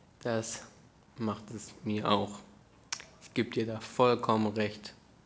German_Conversational_Speech_Data_by_Mobile_Phone